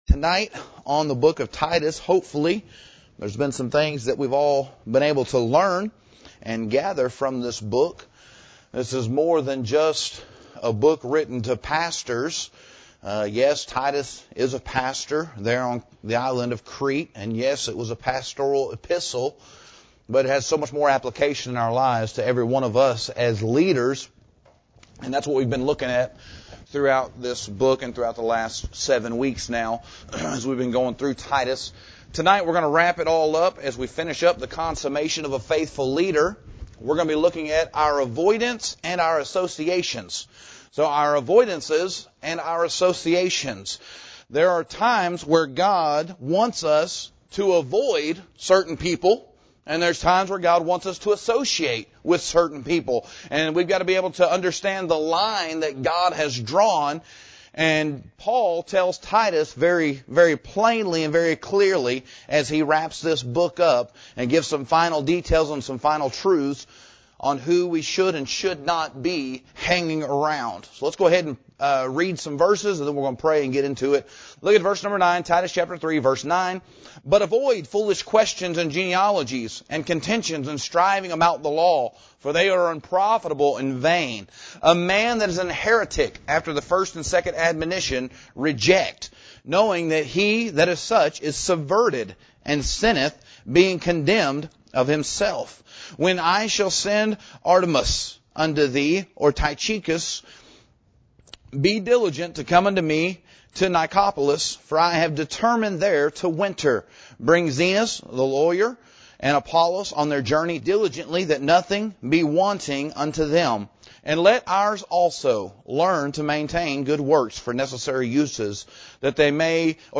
This sermon concludes our series on “Leading Like Jesus” from the Book of Titus.